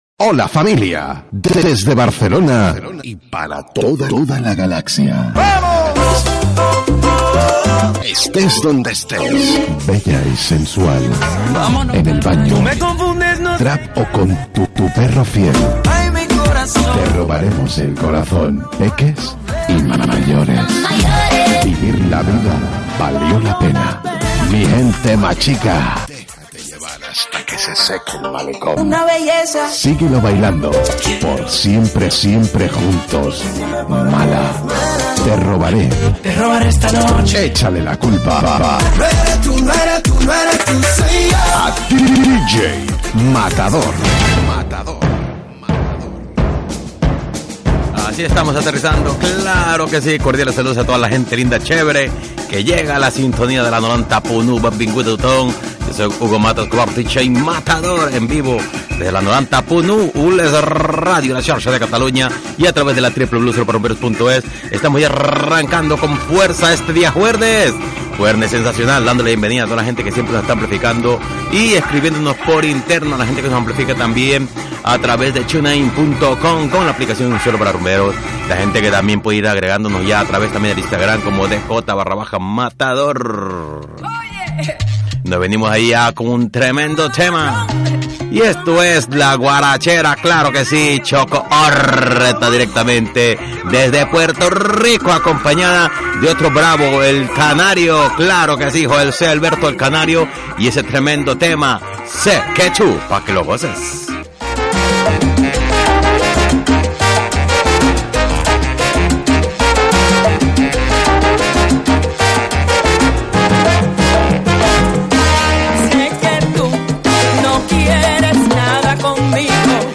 Careta del programa, salutació i tema musical
Musical